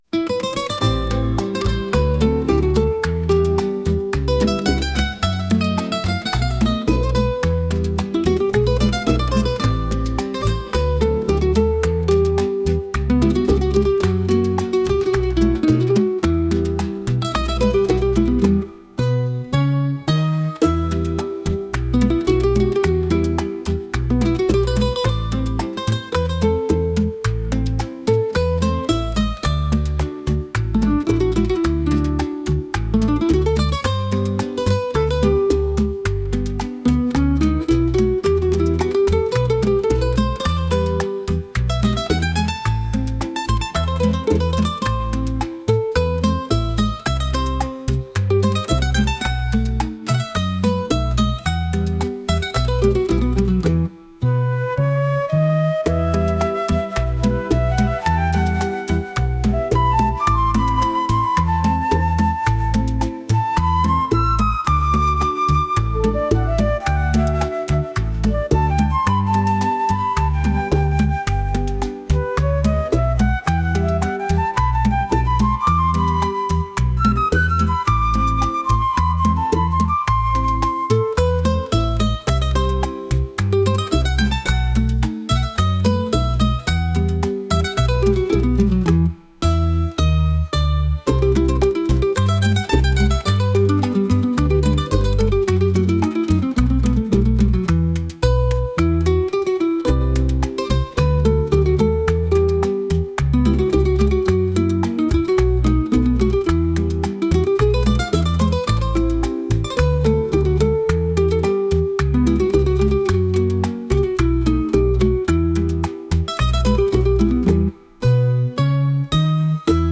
La_penultima_bachata_instrumental_v1.mp3